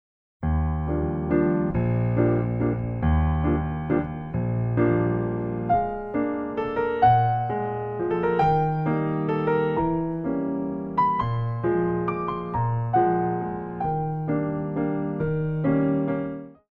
Pirouette (Faster)